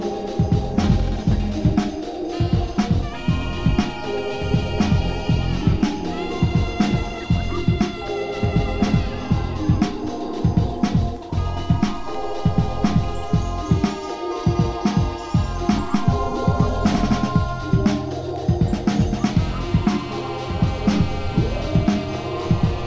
Electro RIngtones